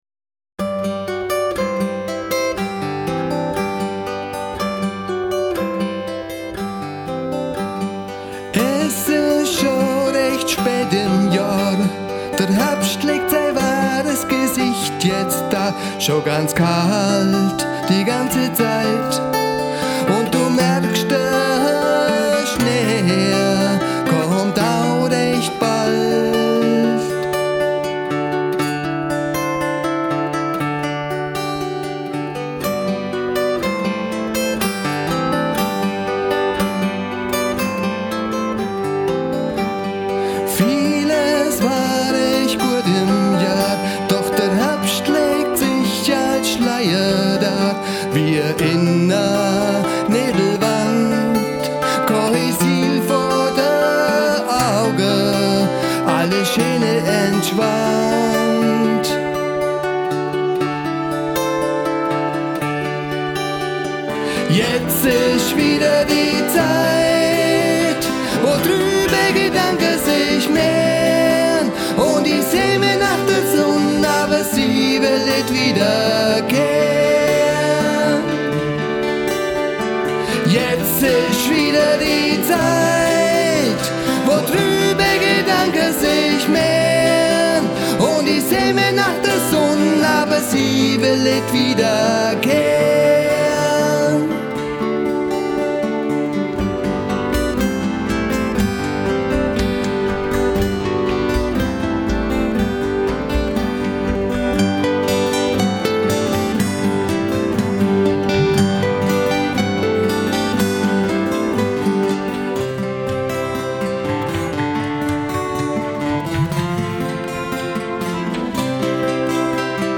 Gitarre und Gesang
Aufnahmesessions (Demo-Aufnahmen) bei mir im Home-Studio: